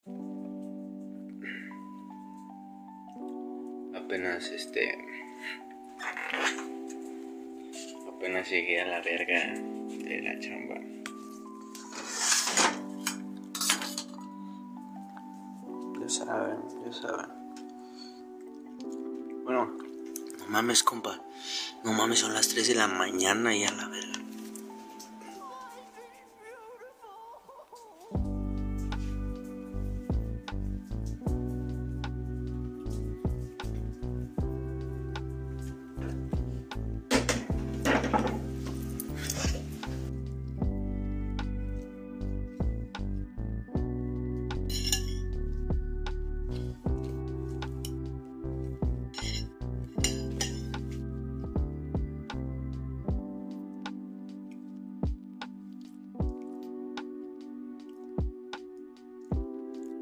A few Mp3 Sound Effect Sneak peek alert! A few moments from my LIVE.